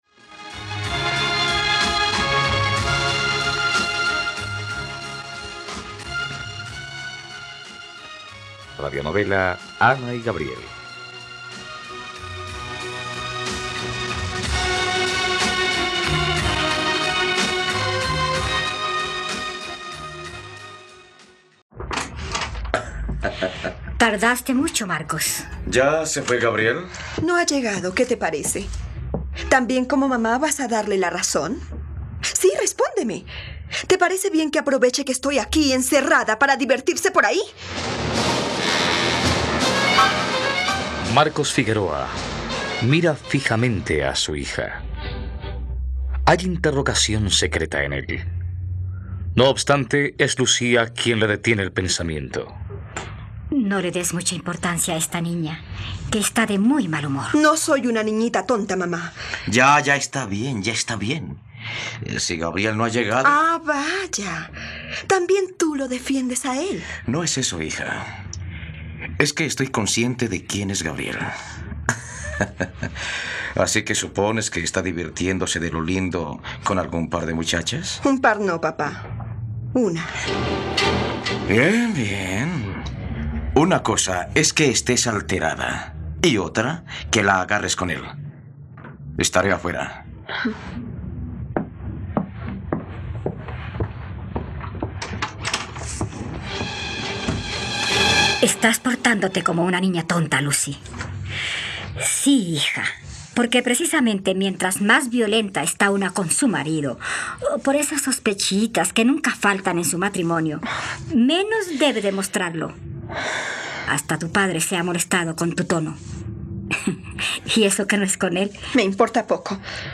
..Radionovela. Escucha ahora el capítulo 63 de la historia de amor de Ana y Gabriel en la plataforma de streaming de los colombianos: RTVCPlay.